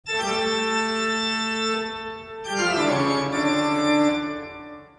1 channel
pipeorg.mp3